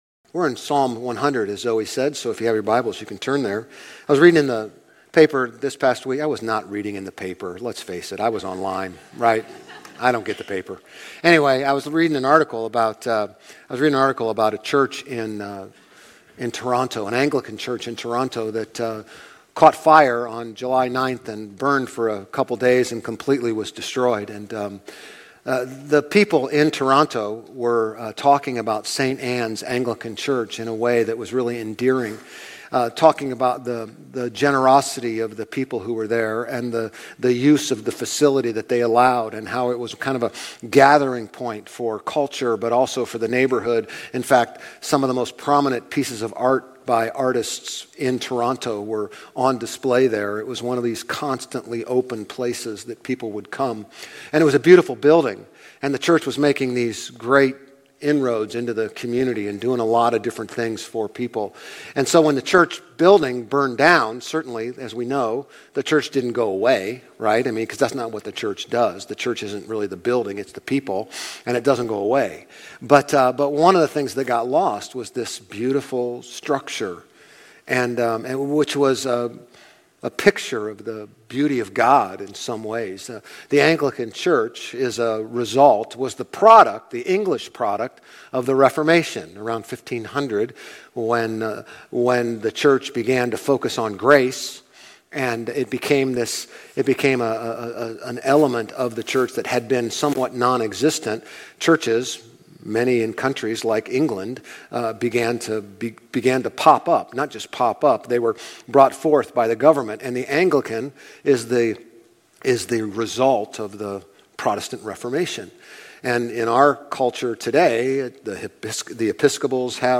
Grace Community Church Old Jacksonville Campus Sermons Psalm 100 - Thanksgiving Jul 07 2024 | 00:36:49 1x 00:00 / 00:36:49 Subscribe Share RSS Feed Share Link Embed